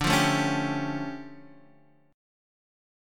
DmM11 chord